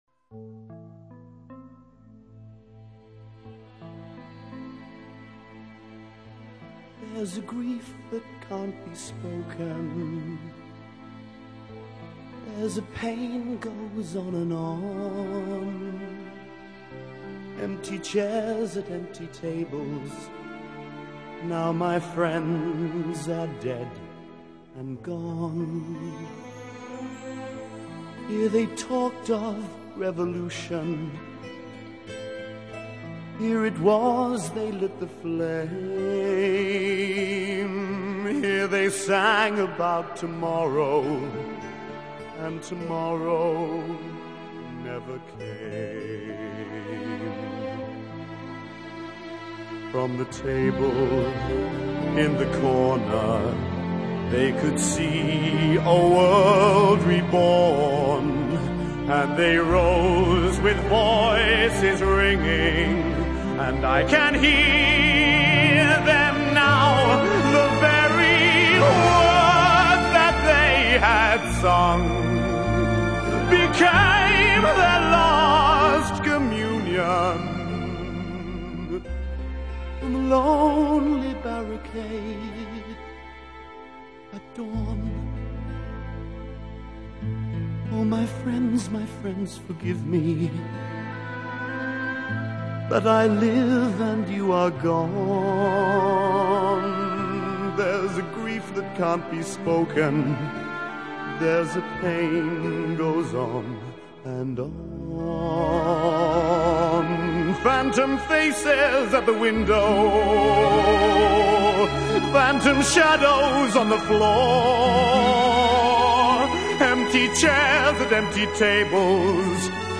倫敦版的配樂，雖然